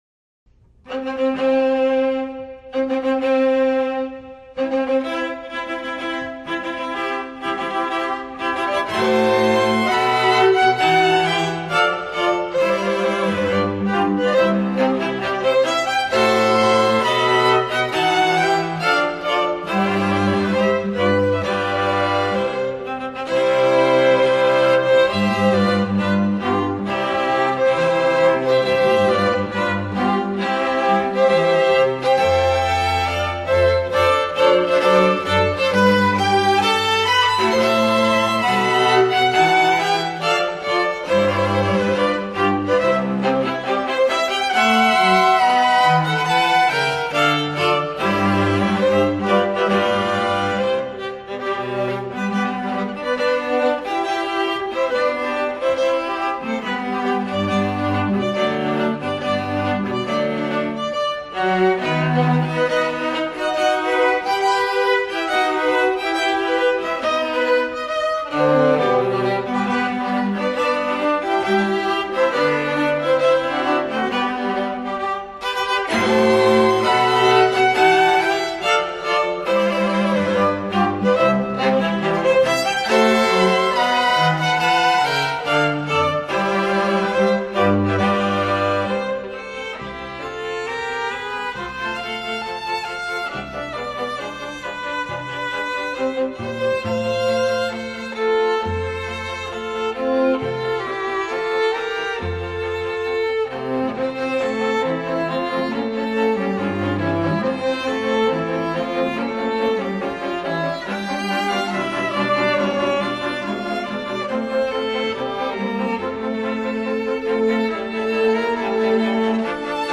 0490-小提琴版婚礼进行曲(1).mp3